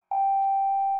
MRT-message-received.mp3